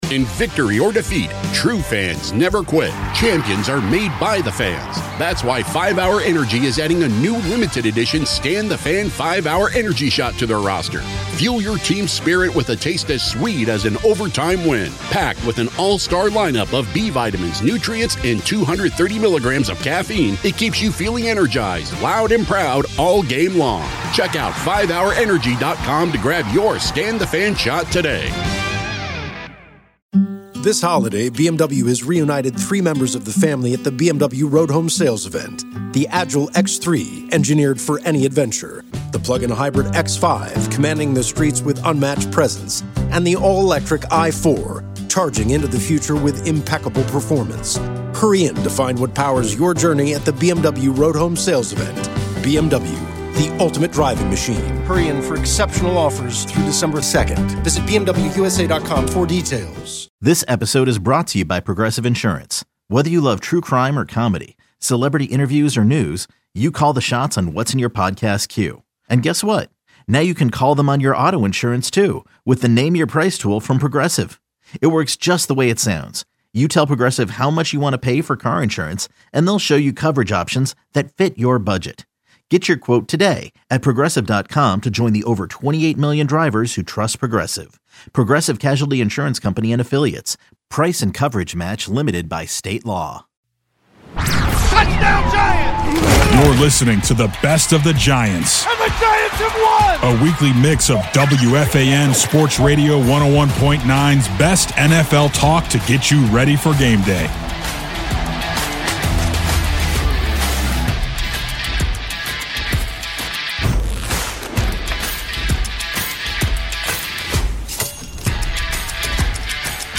Boomer Esiason and Gregg Giannotti talk sports and interview the hottest names in sports and entertainment.